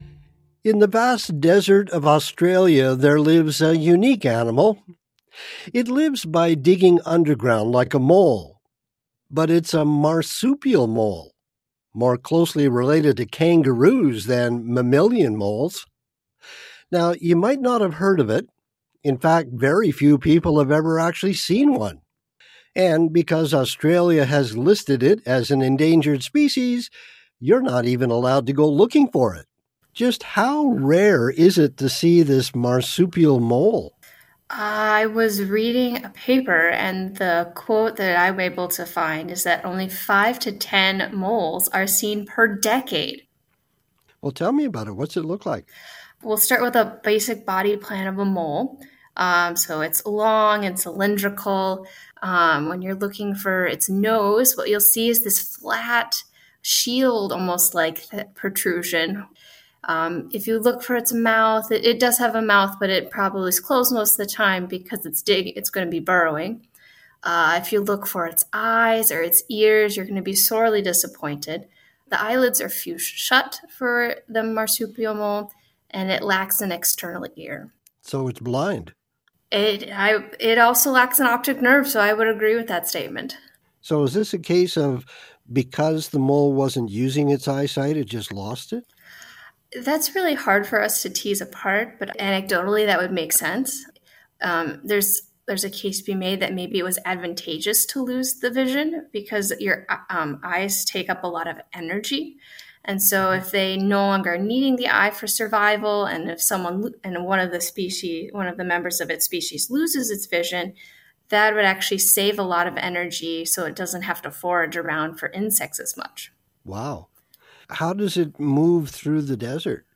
Accent: American